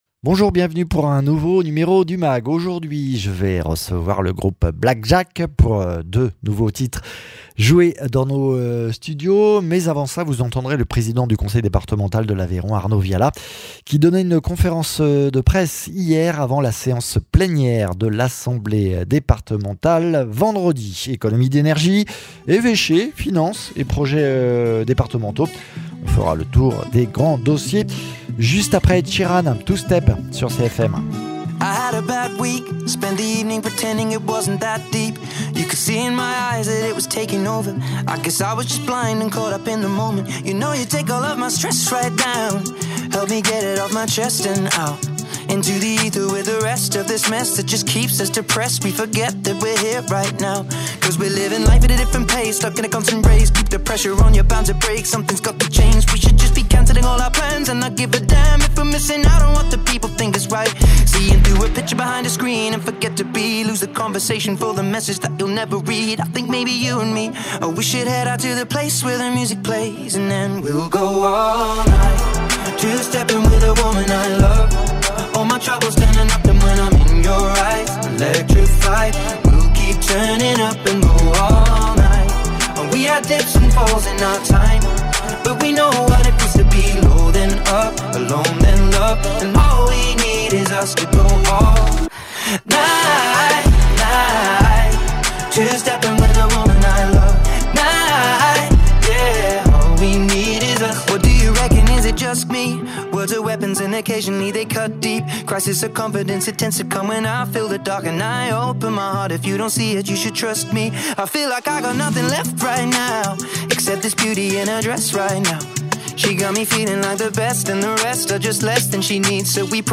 Avant la séance plénière de l’assemblée départementale vendredi, le Président du conseil départemental fait un grand tour des sujets du moment ; dans le mag également Black Jacques, le groupe est venu nous jouer deux nouveaux morceaux